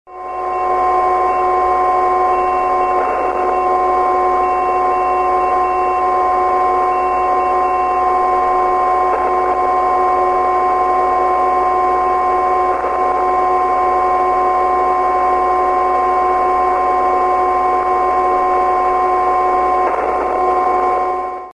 Noise Source: Unidentified, but seems computer/internet related
14,028 to 14,034 kHz and other areas
The same sounding interference is on the low end of 30m as well - around 10.106 to 10.108.